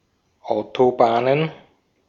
Ääntäminen
Ääntäminen Tuntematon aksentti: IPA: /ˈʔaʊ̯toˌbaːnən/ IPA: /ˈaʊ̯toˑˌbaːnən/ Haettu sana löytyi näillä lähdekielillä: saksa Käännöksiä ei löytynyt valitulle kohdekielelle. Autobahnen on sanan Autobahn monikko.